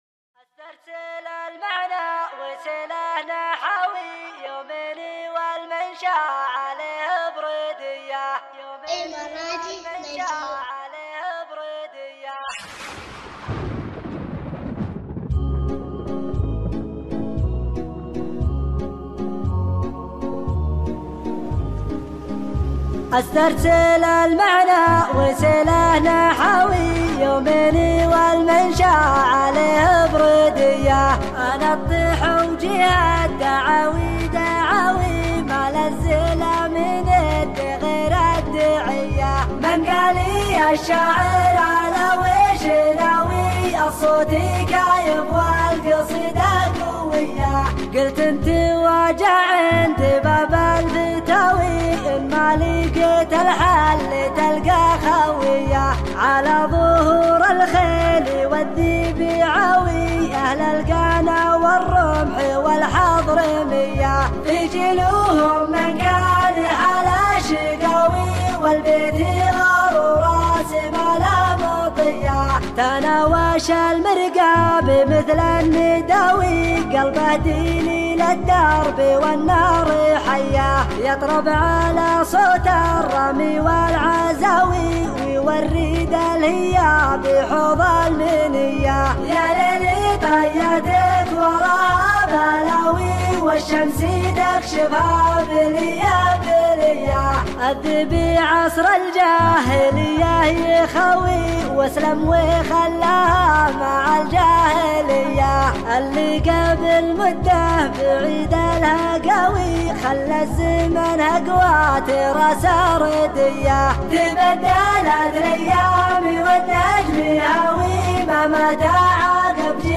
مسرع